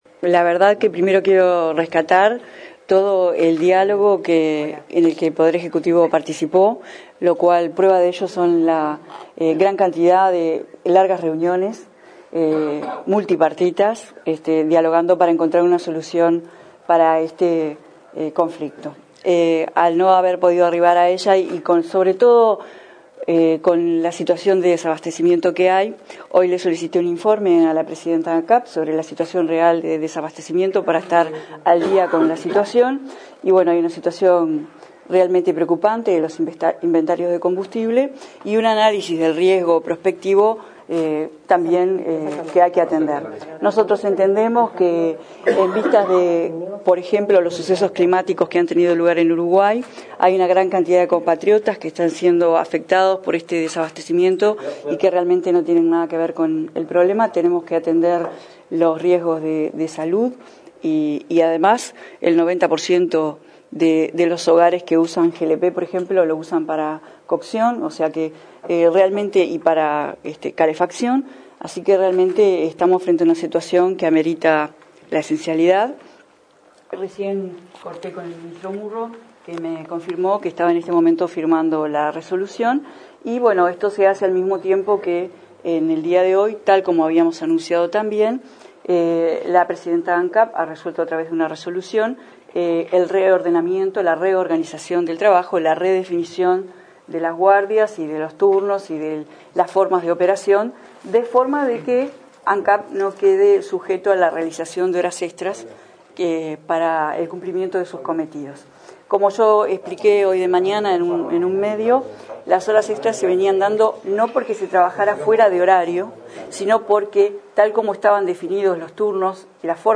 A partir de este lunes 12 rige la esencialidad para los servicios de importación, producción, distribución y comercialización de todos los combustibles. La ministra de Industria, Energía y Minería, Carolina Cosse, explicó que el gobierno adoptó esa medida tras varias reuniones con las partes del sector en una apuesta al diálogo, sin encontrar una solución ante el conflicto con el sindicato de trabajadores de Ancap.